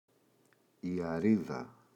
αρίδα, η [a’riða]